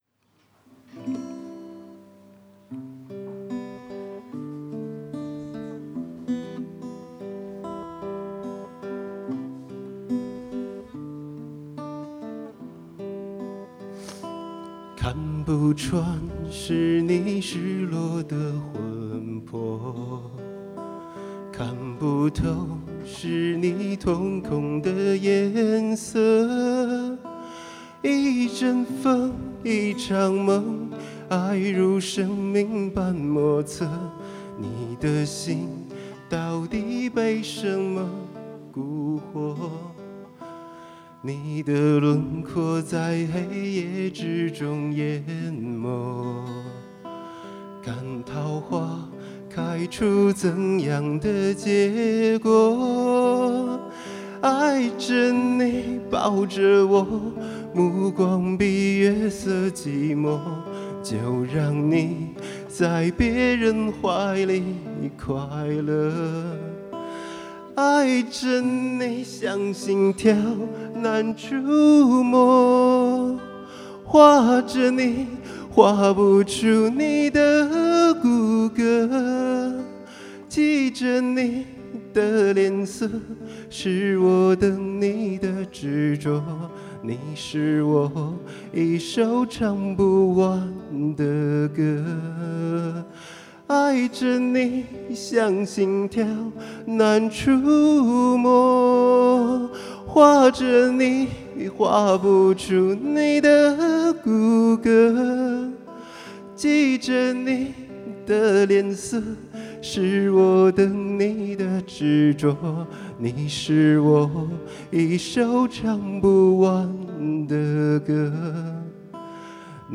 Le concours de chansons chinoises 2018